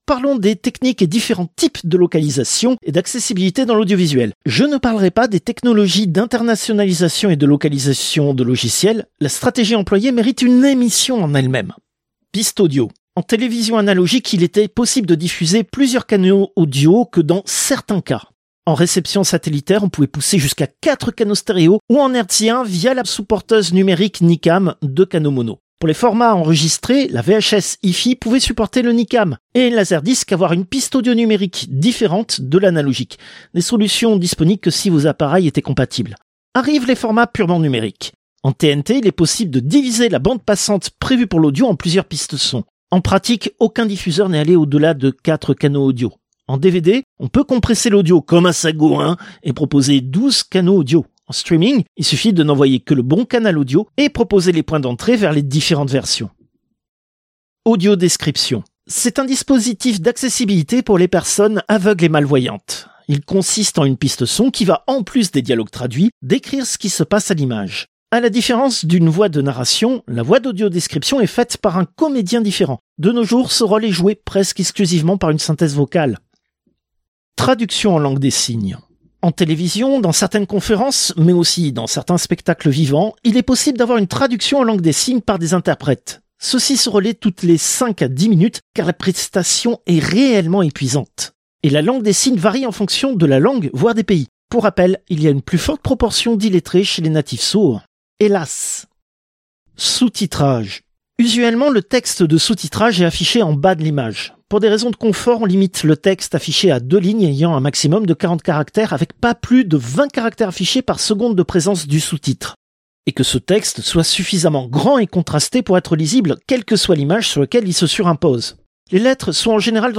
Extrait de l'émission CPU release Ex0236 : Doublage robot, seconde partie.